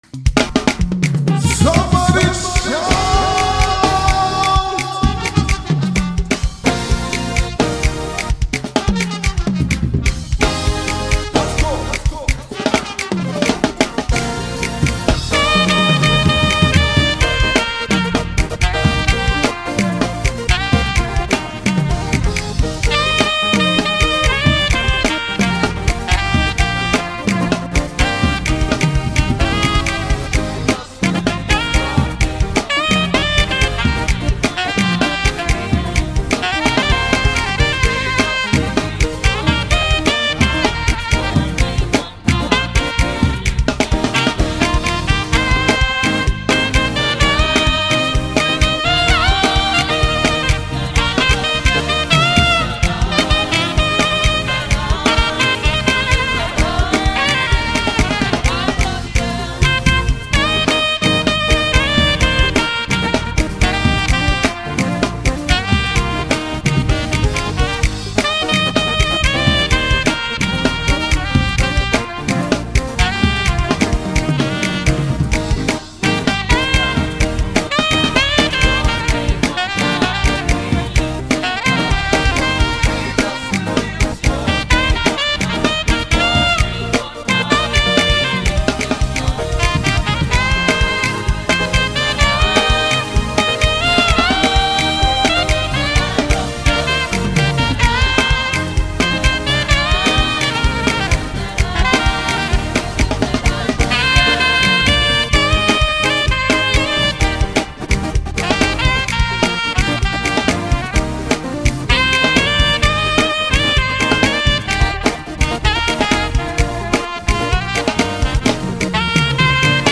Gospel Saxophonist